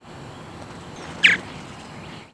Purple Martin Progne subis
Flight call description Most common flight call is a rich descending "cheur".
"Cheur" call variation from perched bird in mild alarm.